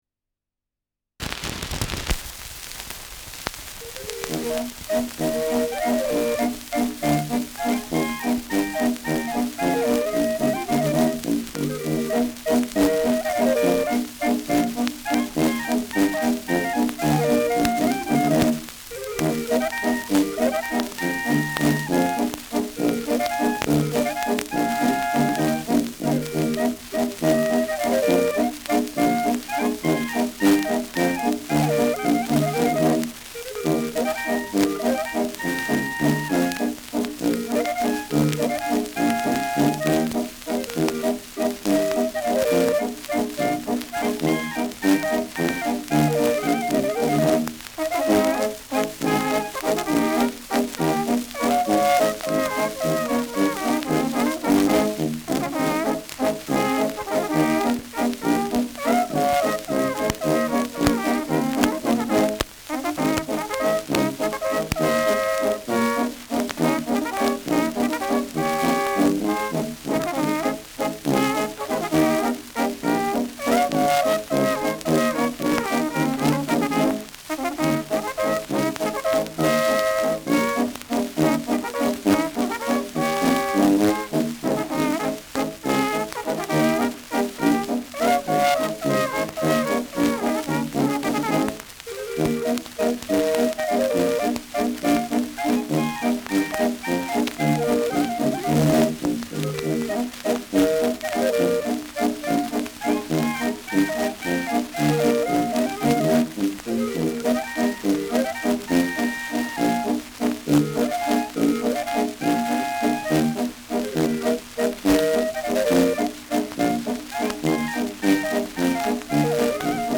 Schellackplatte
Stärkeres Grundrauschen : Durchgehend leichtes bis stärkeres Knacken
Kapelle Bosl, Falkenstein (Interpretation)